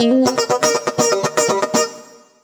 120FUNKY13.wav